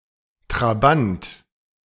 Trabant (German: [tʁaˈbant]